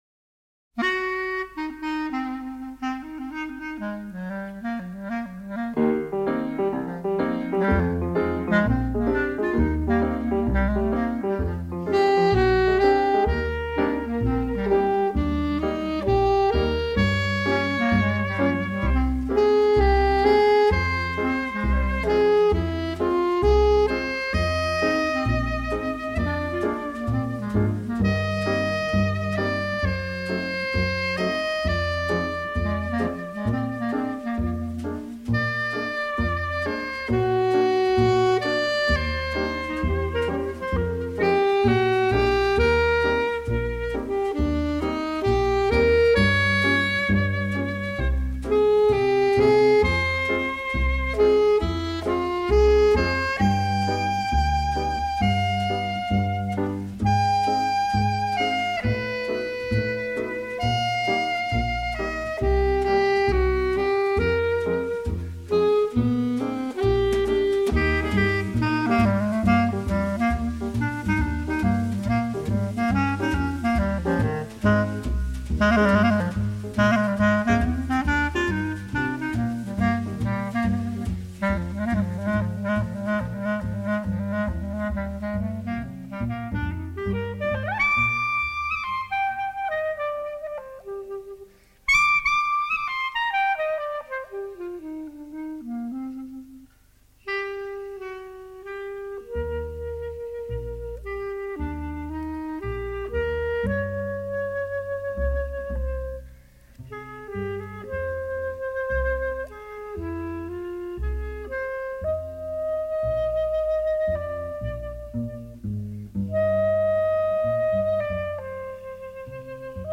C’est jazzy avec cha-cha, swing et mambo.
C’est frais, varié et généreux !